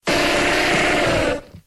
Cri de Gravalanch dans Pokémon X et Y.